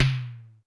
Drums_K4(06).wav